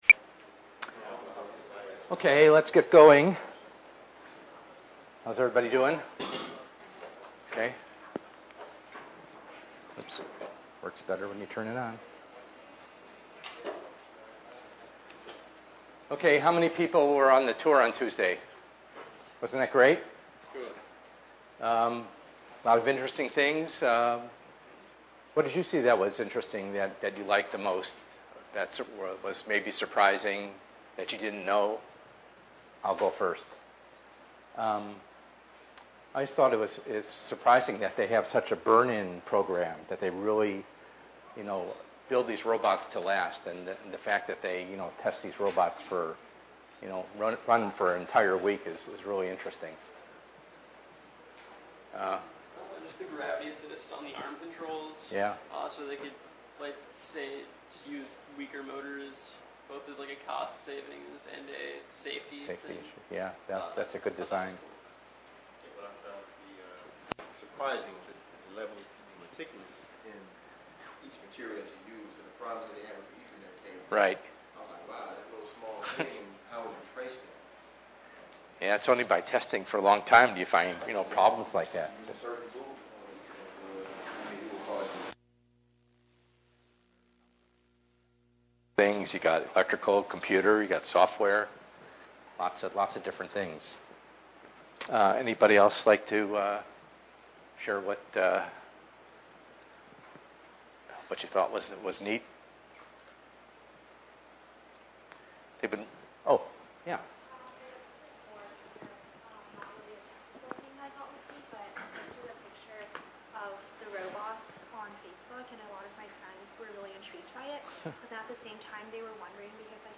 ENGR110/210: Perspectives in Assistive Technology - Lecture 05b